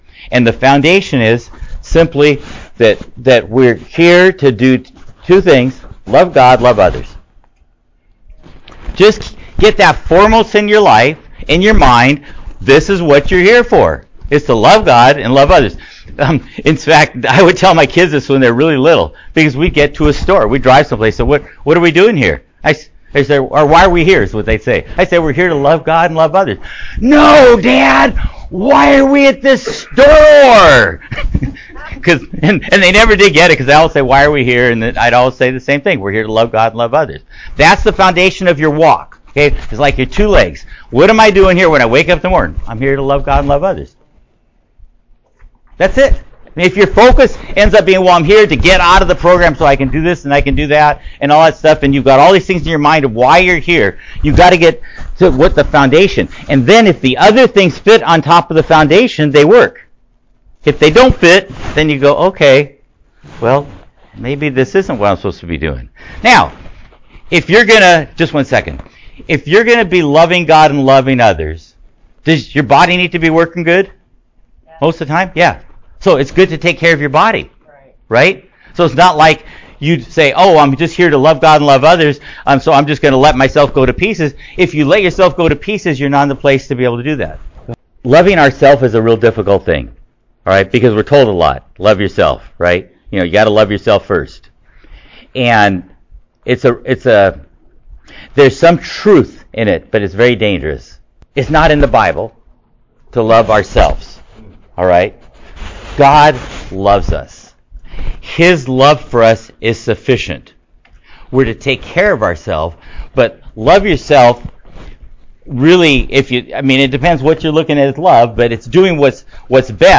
These are excerpts from messages given to those in a recovery program.